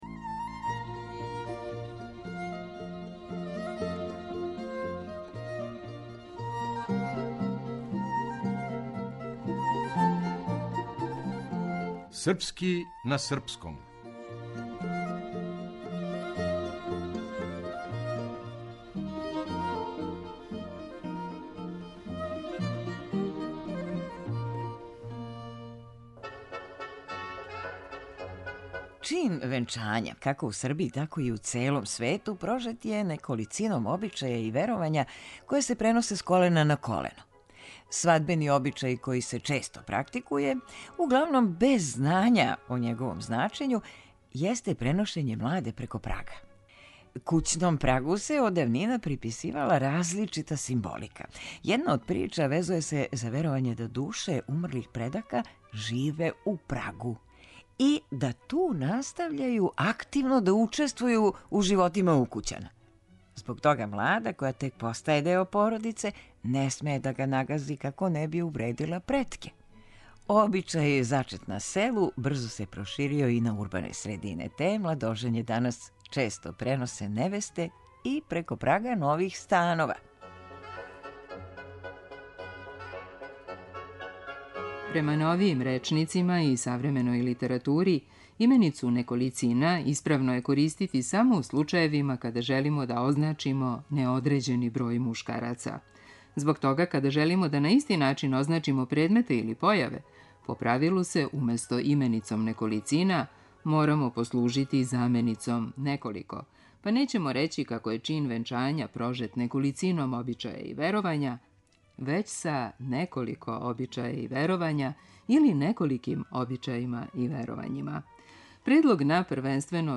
Глумица